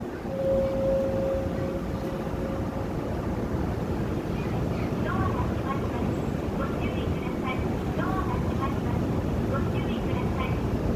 ・50070系乗降促進
【東横線・みなとみらい線内】
副都心線・有楽町線ではメロディ（※スイッチ管理）、それ以外ではブザーが流れます。ブザー後の放送が路線によって異なります。